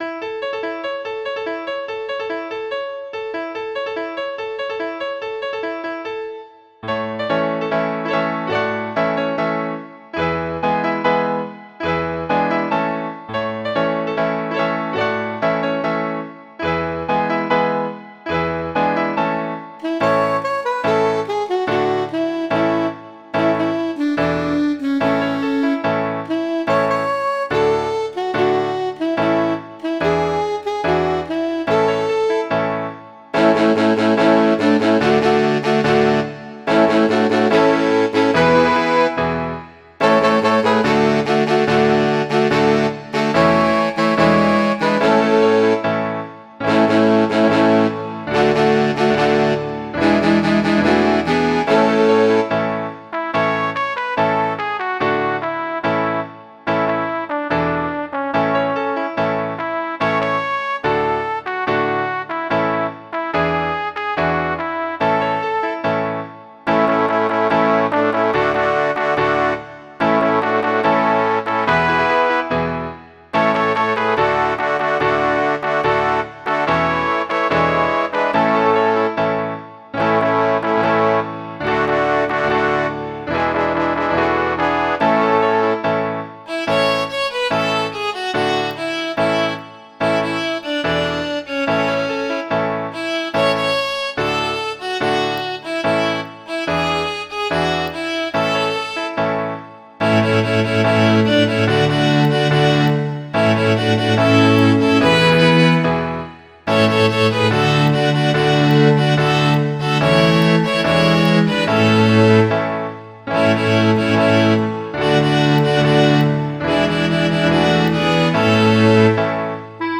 Midi File, Lyrics and Information to Tenting Tonight